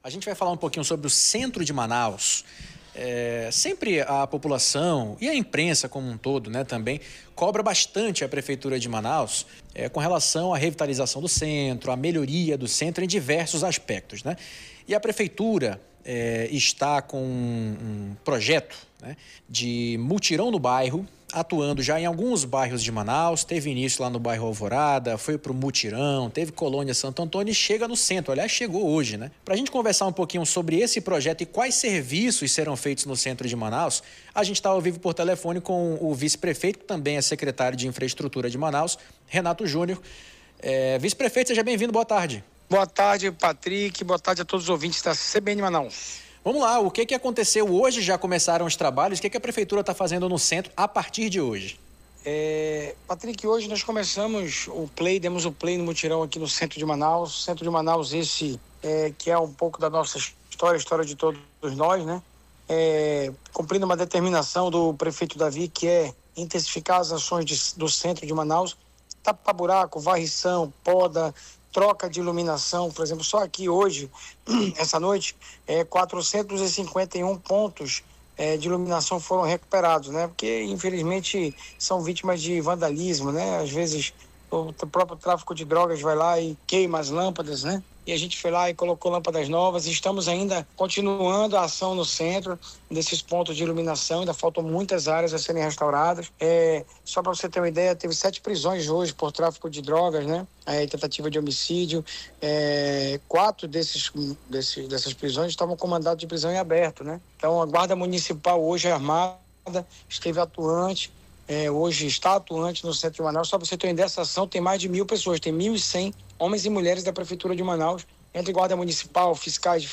Em entrevista ao CBN Tarde de Notícias, o vice-prefeito Renato Júnior, que vai coordenar toda a ação, afirmou que o "Mutirão no Centro é a maior operação já realizada pela Prefeitura de Manaus na região".
ENTREVISTA-RENATO-JR.mp3